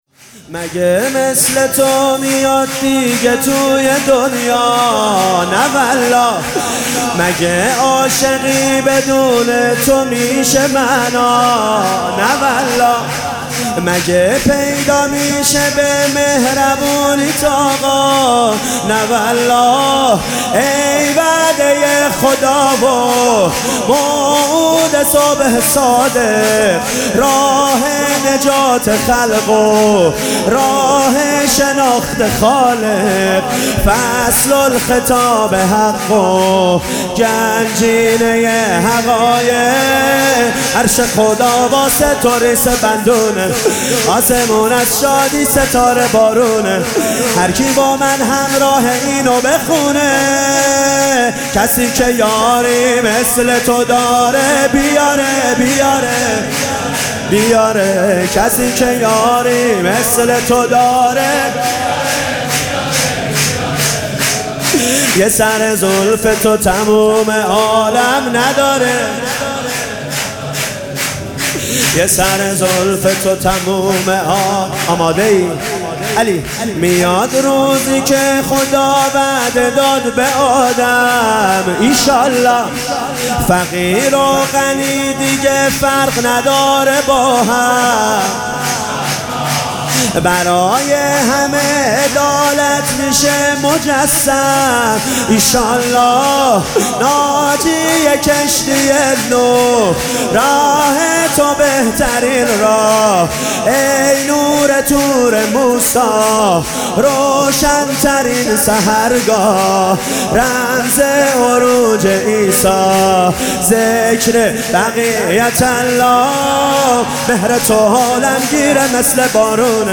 مراسم جشن ولادت حضرت صاحب_الزمان (عج)
سرود
جشن نیمه شعبان